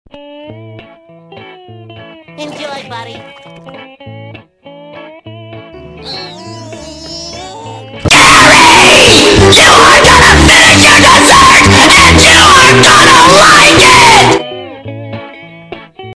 ⚠Volume warning!⚠ I still love sound effects free download